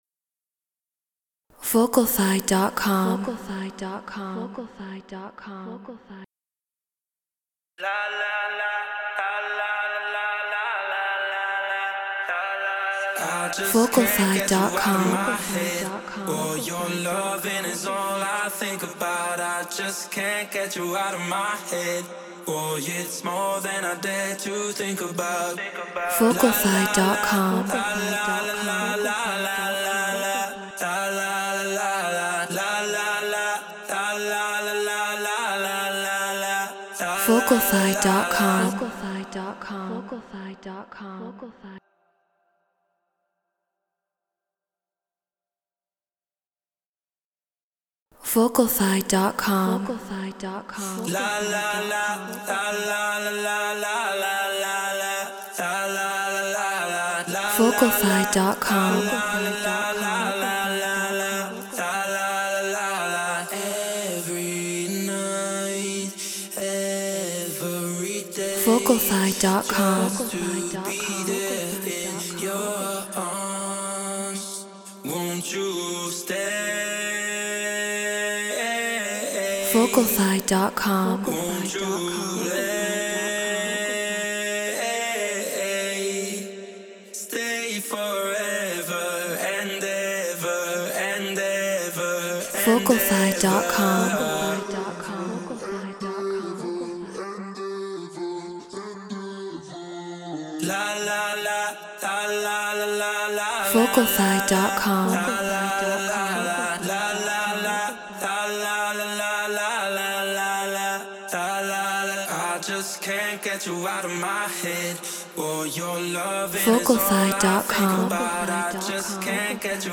Hard Dance 155 BPM Fmin